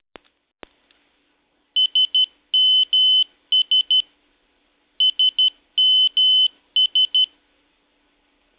如果你用过老旧的 Nokia 手机，可能听到过它收到短信时会发出一种“特殊”的铃声：“嘀嘀嘀,嗒嗒,嘀嘀嘀”，也就是短响三次，长响两次，再短响三次。这实际上是摩斯密码的“SMS”(短信服务)的意思。
Nokia 的短信息铃声 MP3.
Nokia_Old_Sms_Tone.mp3